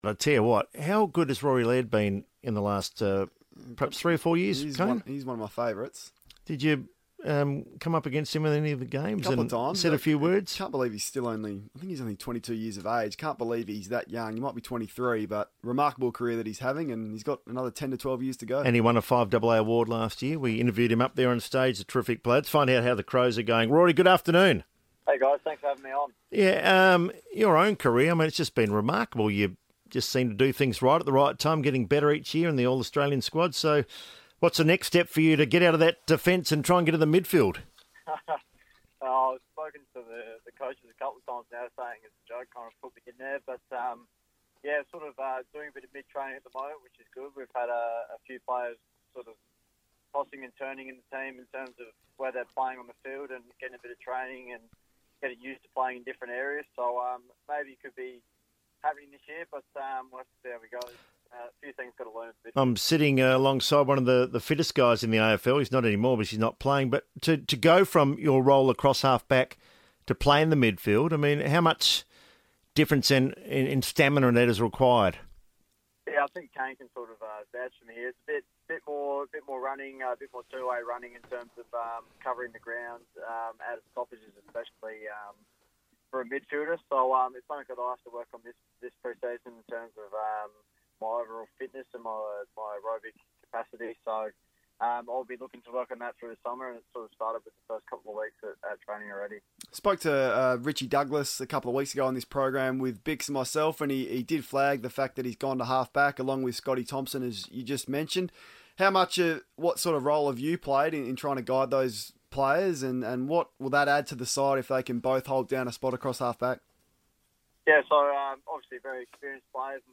Crows defender Rory Laird spoke on FIVEaa radio as the Club prepares to break for the end-of-year holiday period